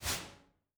Swing sword_1.wav